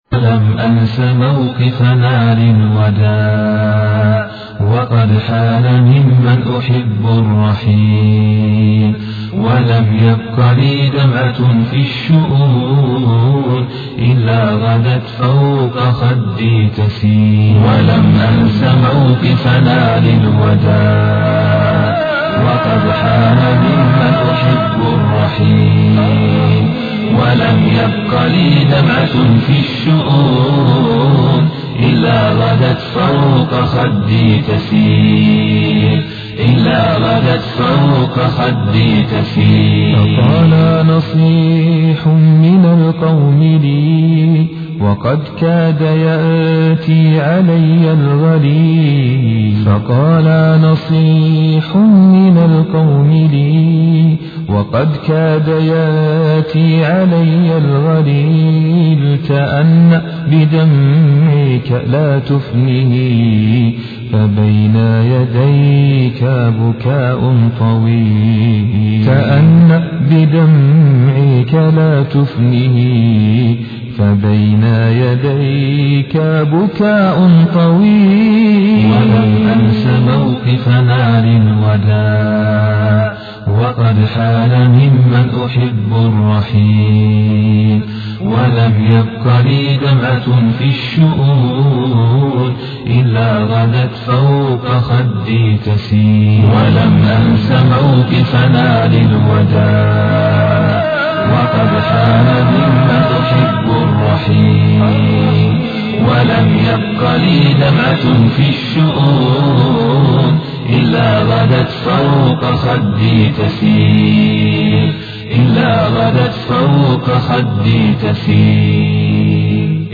Description: - Tags: نشيد ( 0 ) ( 0 Votes ) 1 2 3 4 5 Bookmark Blink Digg Furl Deli Google Report Abuse Add Favorites Download audio